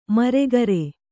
♪ maregare